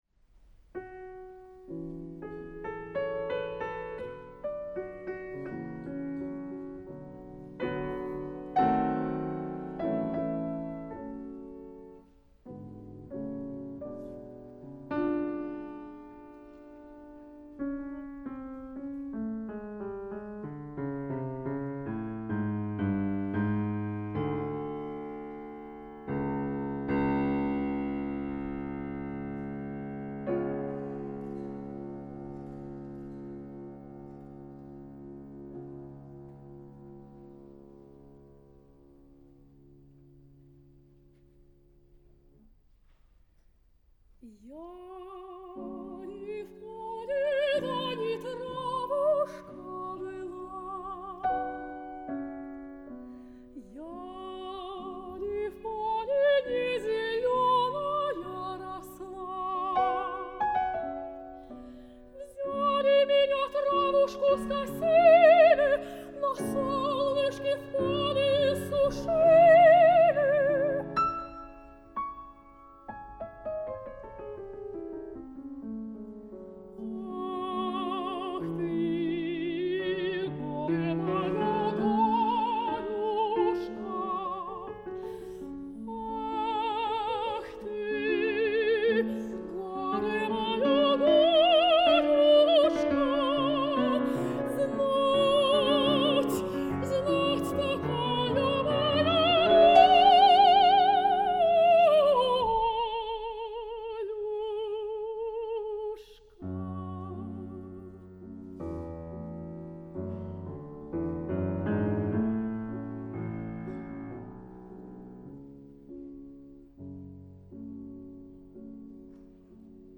Аудио: Петр Ильич Чайковский Романс «Я ли в поле да не травушка была»